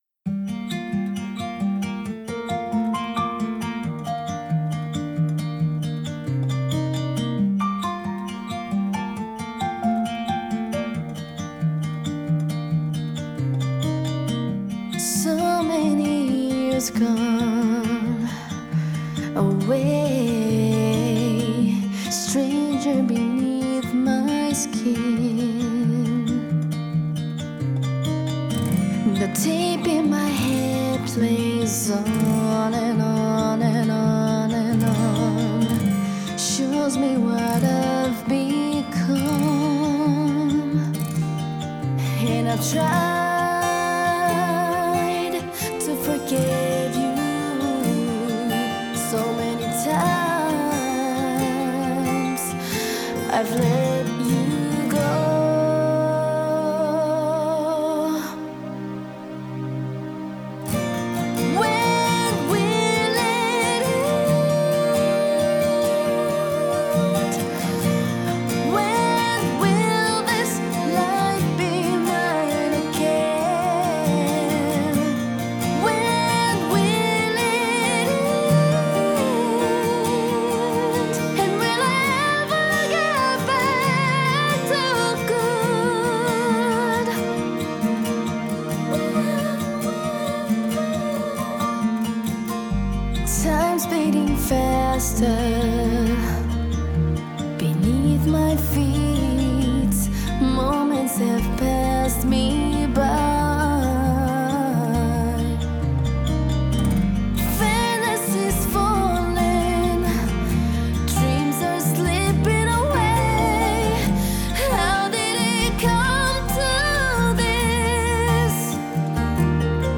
• Genre: Pop